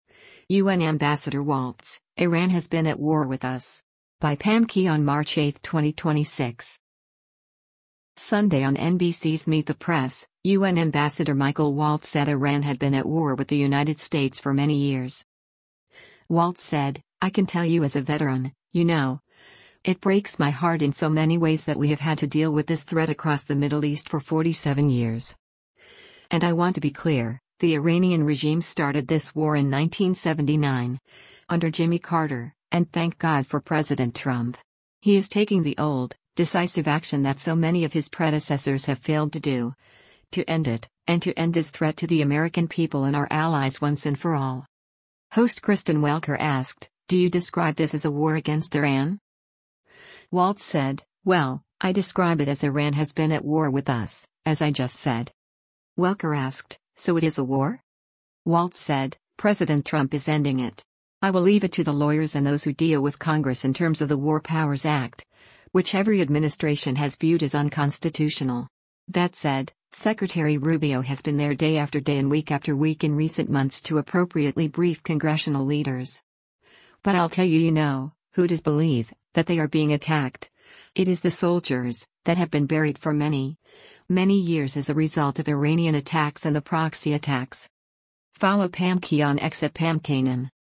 Sunday on NBC’s “Meet the Press,” U.N. Ambassador Michael Waltz said Iran had been at war with the United States for many years.